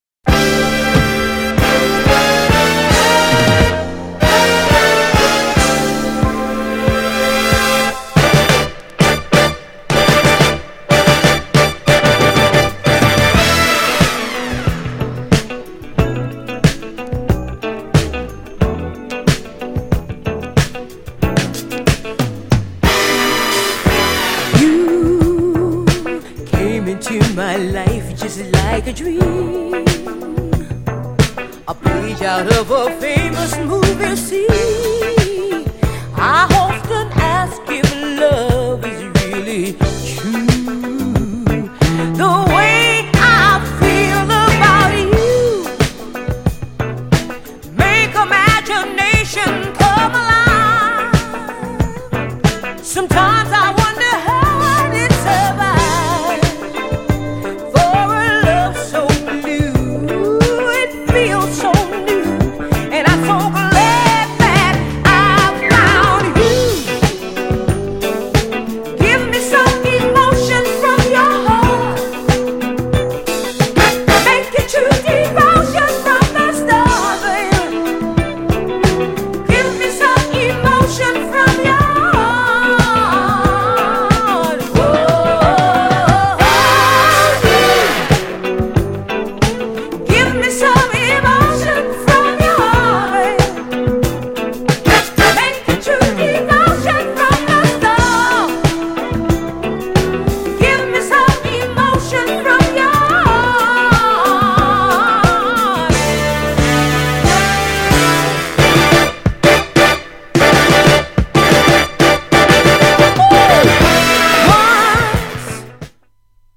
GENRE Dance Classic
BPM 106〜110BPM